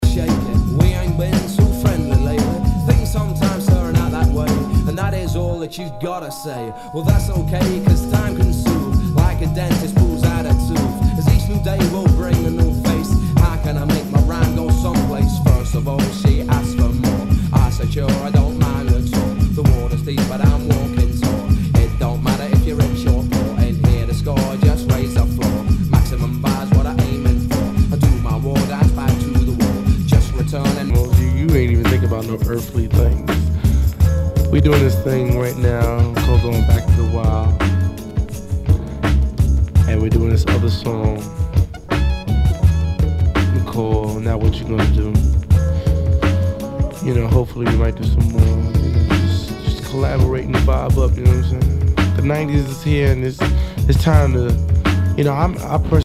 HIPHOP/R&B
ナイス！ヒップホップ / ダウンテンポ！